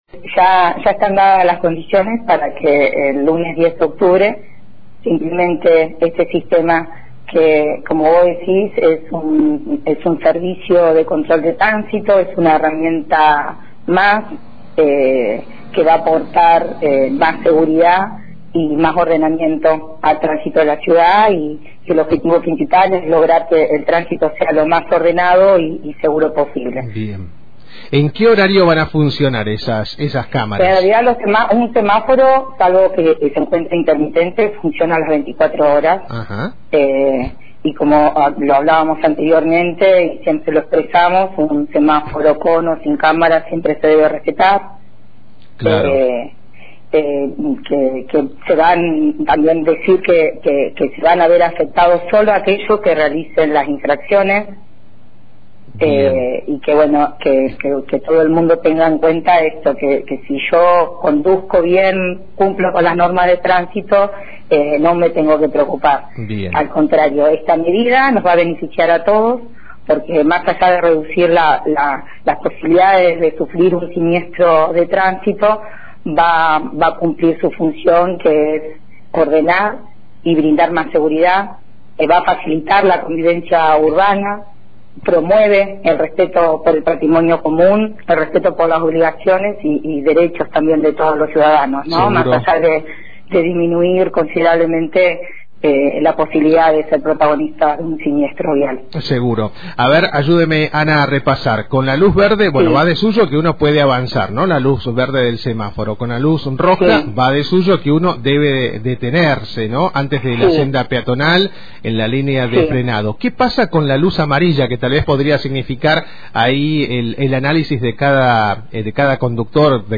El próximo lunes 10 de octubre comenzará a funcionar el sistema de fotomultas en la ciudad de Fiske Menuco-roca, y para brindar mayor información sobre la nueva medida conversamos con Ana Campos, Directora de Tránsito Municipal.
Ana-Campos-Directora-de-Transito.mp3